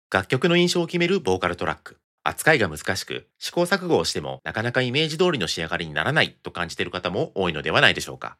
▼差し替え用に準備した音声（ナレーション）